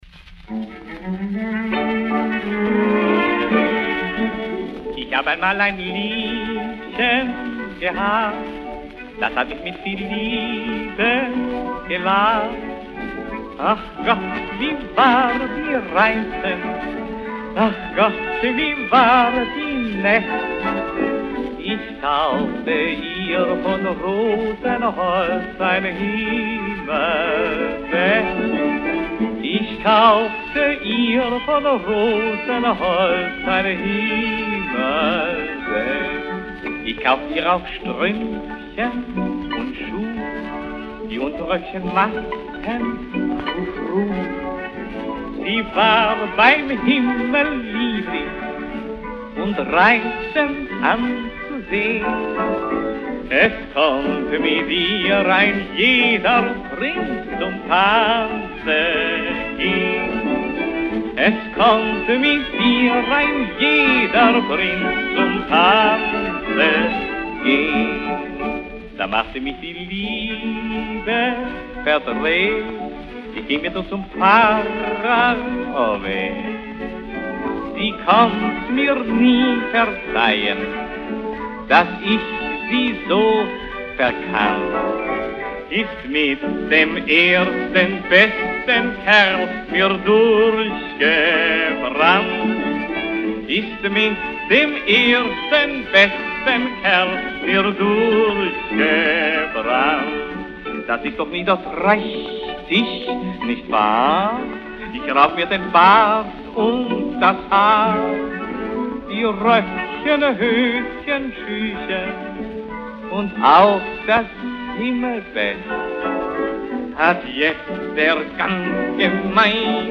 Schellackplattensammlung